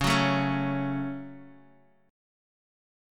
C#sus4#5 chord